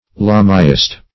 (Buddhism) an adherent of Lamaism ; The Collaborative International Dictionary of English v.0.48: Lamaist \La"ma*ist\, Lamaite \La"ma*ite\n. One who believes in Lamaism.